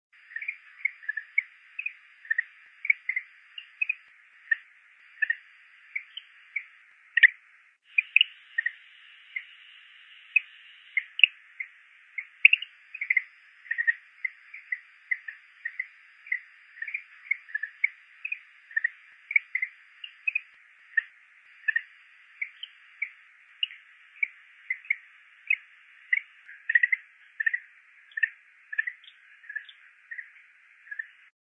Greenhouse_Frog.mp3